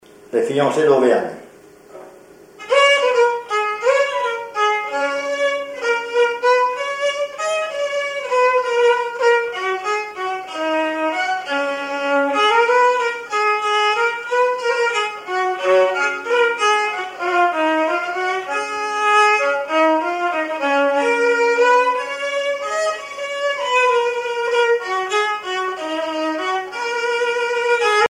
violoneux, violon
danse : valse musette
Pièce musicale inédite